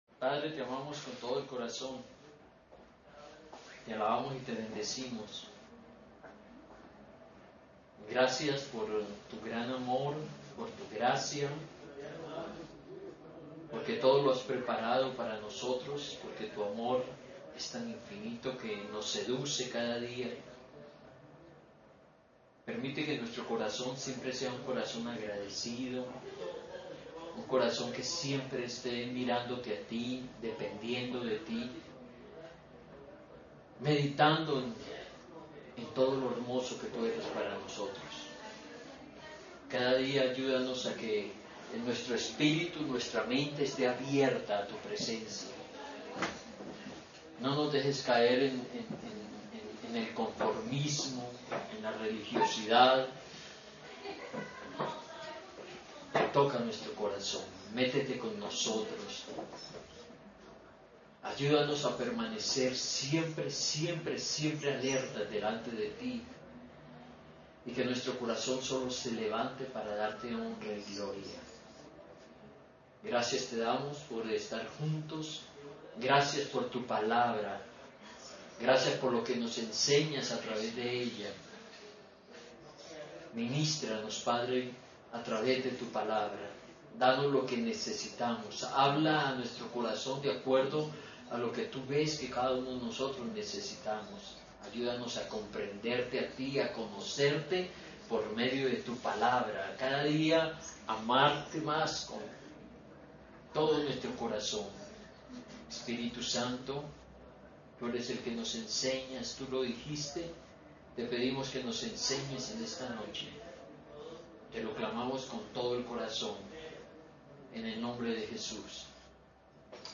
Lección 1: Hebreos (audio 30)